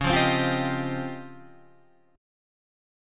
C#M#11 chord